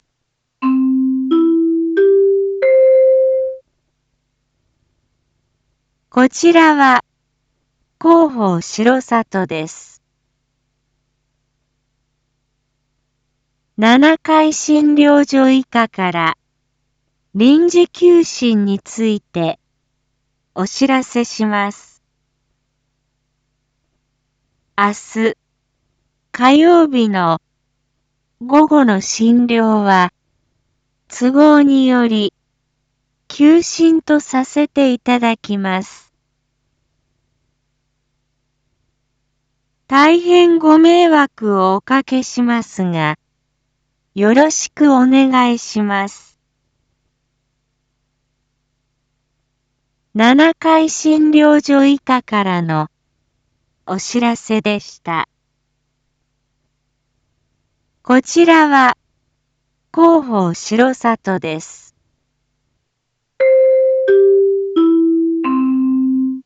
一般放送情報
Back Home 一般放送情報 音声放送 再生 一般放送情報 登録日時：2024-07-15 19:01:13 タイトル：①七会診療所医科臨時休診のお知らせ インフォメーション：こちらは広報しろさとです。